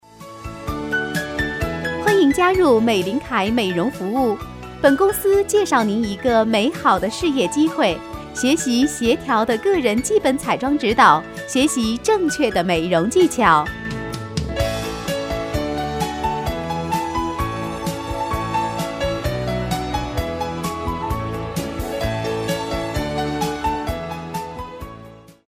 女声配音
彩铃女国37